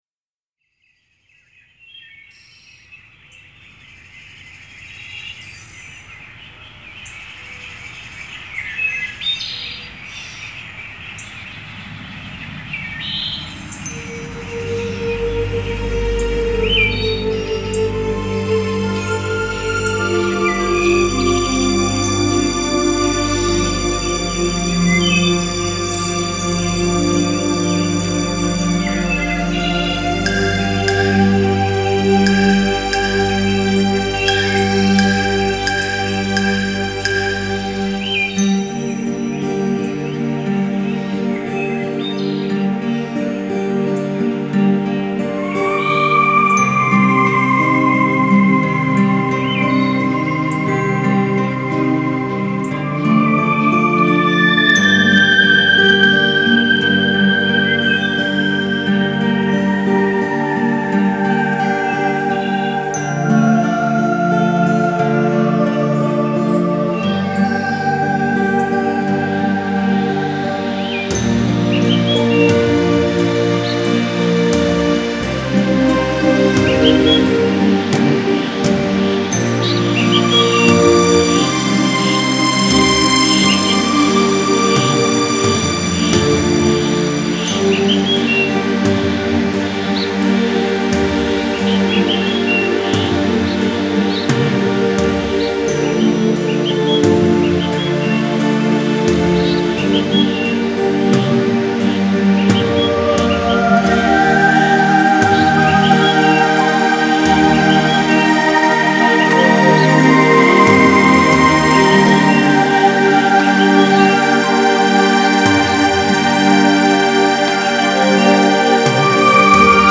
集韩国音乐之精华，应用世界音乐风格的编曲，加上南朝鲜国宝级的民族器乐演奏家